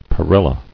[pe·ril·la]